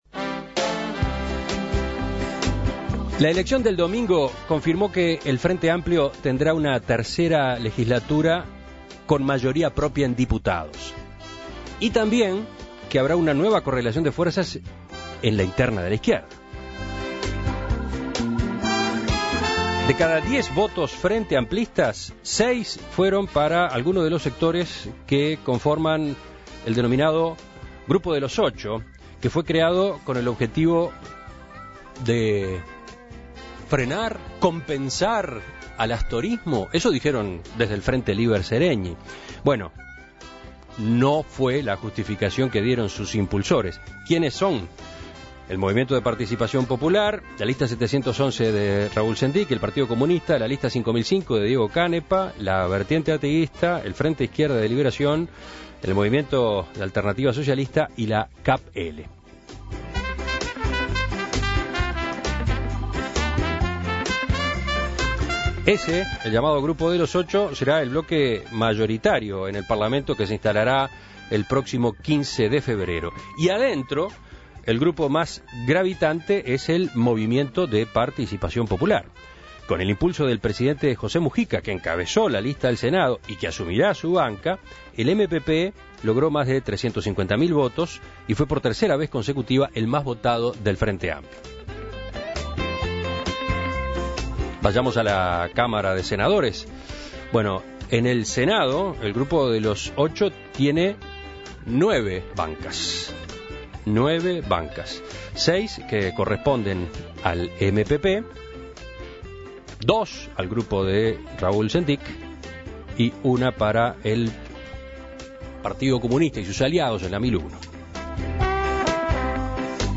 En Perspectiva entrevistó al senador Ernesto Agazzi, nuevamente electo por el Espacio 609.